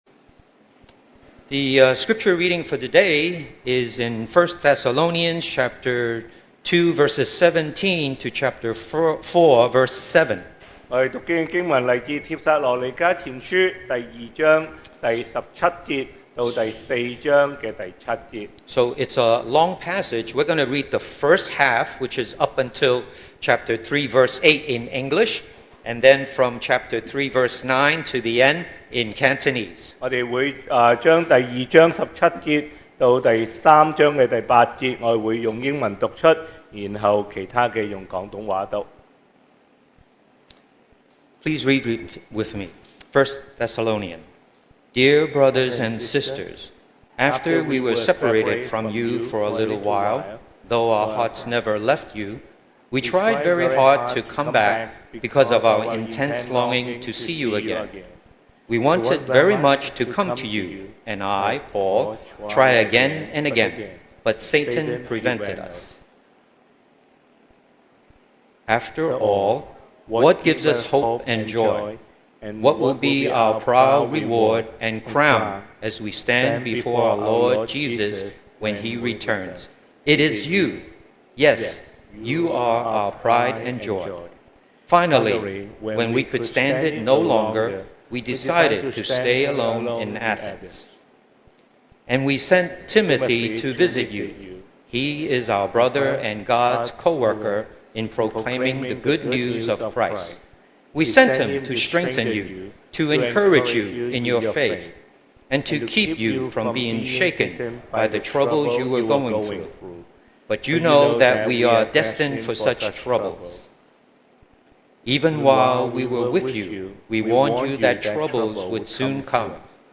2023 sermon audios
Service Type: Sunday Morning